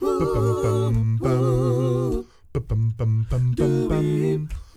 ACCAPELLA 2.wav